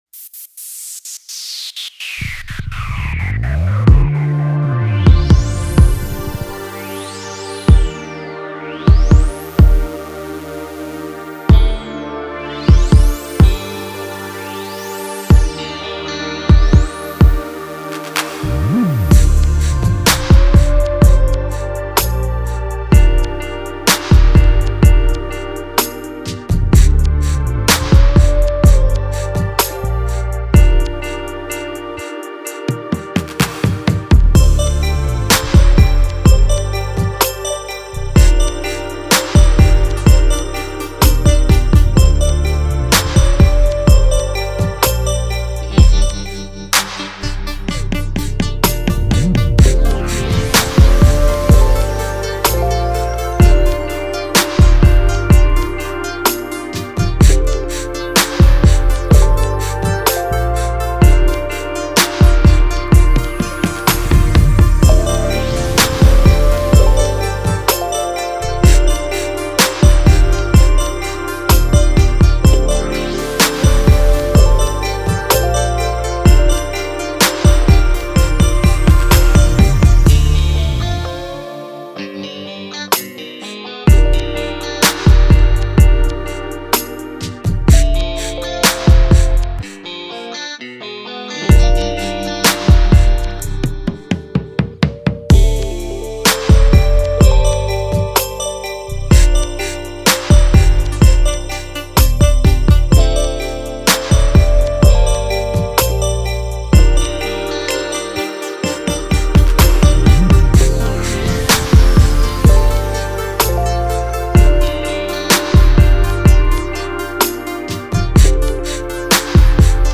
Rhythm and Blues Instrumentals